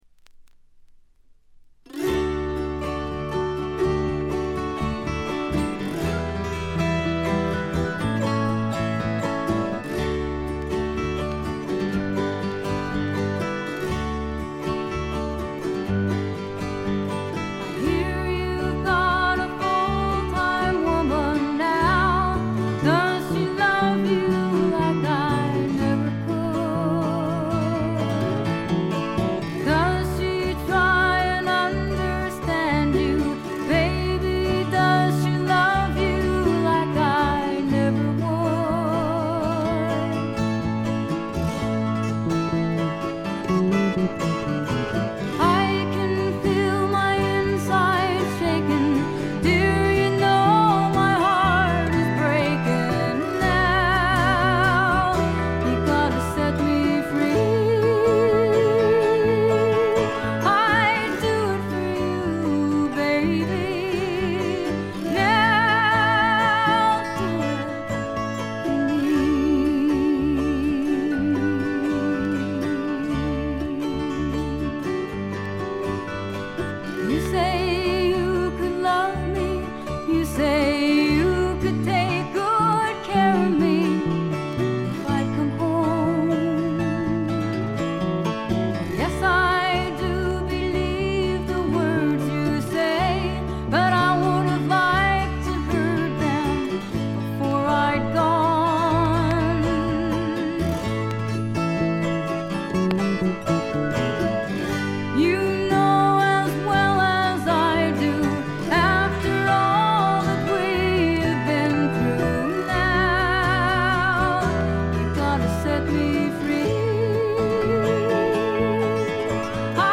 バックグラウンドノイズ、チリプチは大きめで半分ほどでほぼ常時出ます。
アラスカ産の自主制作ヒッピー・フォーク。
音の方は男3女2の構成のオーソドックスなフォークです。
リードヴォーカルはほとんどが美しいフィメールなので、普通にフィメールフォーク作品として聴いていただけます。
いかにもアラスカらしい清澄な空気感と美しい女声ヴォーカルの妙をお楽しみください。
試聴曲は現品からの取り込み音源です。